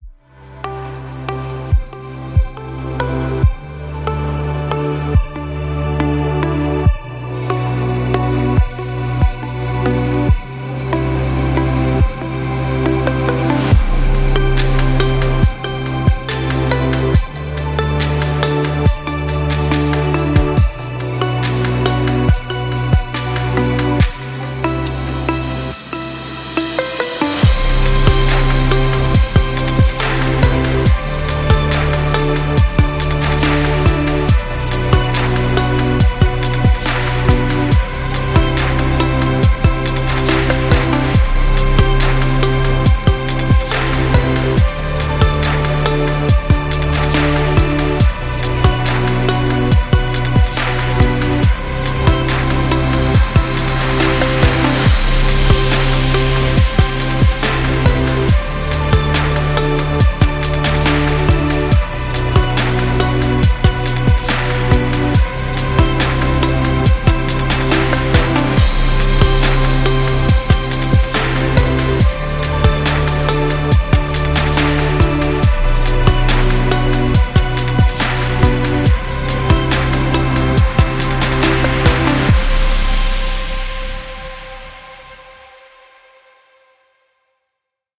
8 - Relax Ambient Music